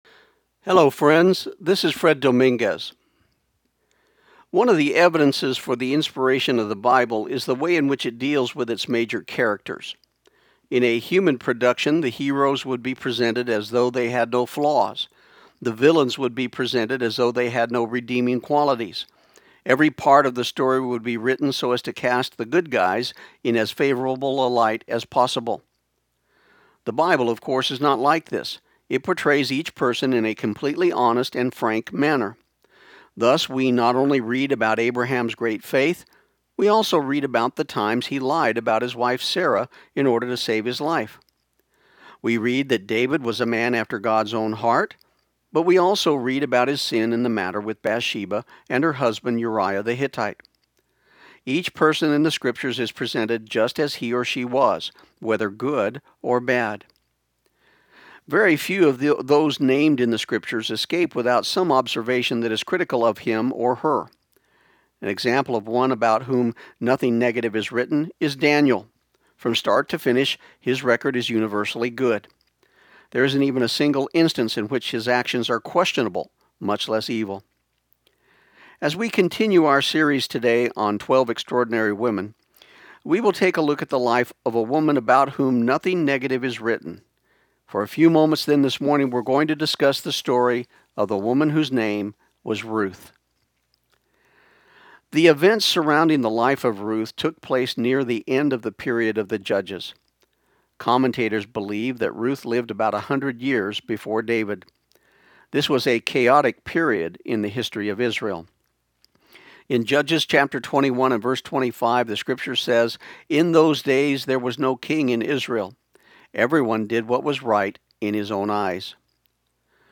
This program aired on KIUN 1400 AM in Pecos, TX on September 4, 2013.